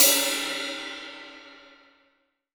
Index of /90_sSampleCDs/AKAI S6000 CD-ROM - Volume 3/Crash_Cymbal1/16-17_INCH_CRASH
DRY 17CRS2-S.WAV